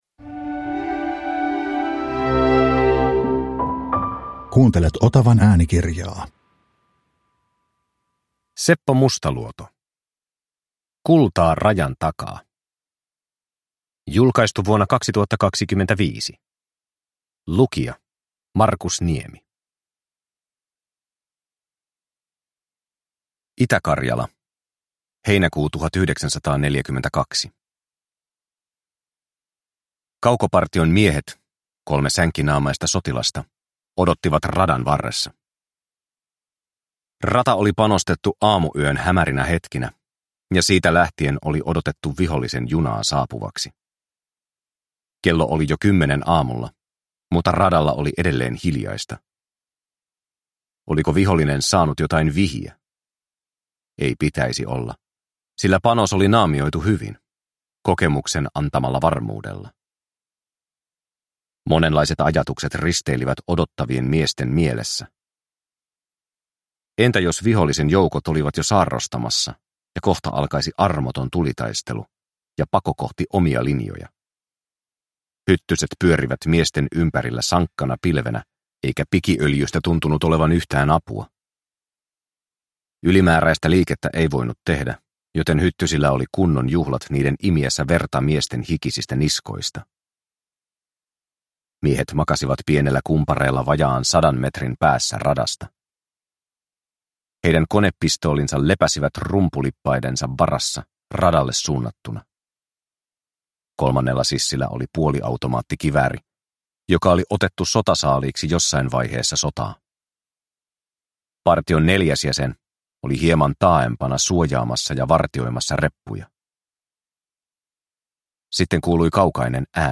Kultaa rajan takaa (ljudbok) av Seppo Mustaluoto